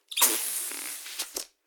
Deflating Balloon
balloon deflate OWI rubber sound effect free sound royalty free Memes